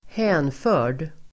Uttal: [²h'ä:nfö:r_d]